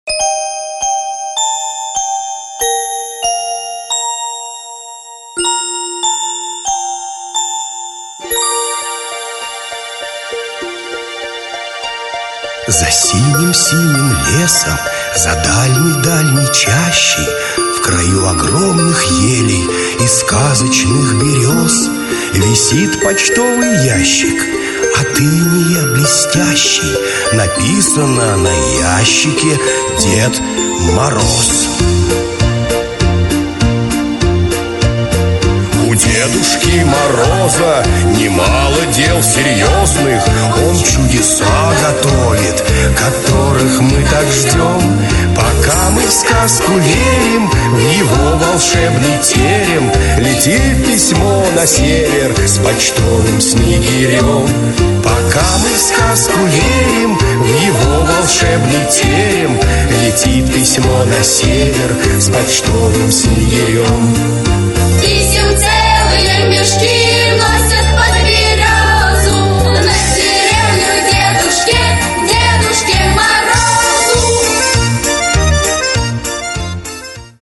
Аудиокнига Дело было в Новый Год!
Автор Андрей Усачев Читает аудиокнигу Актерский коллектив.